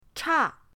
cha4.mp3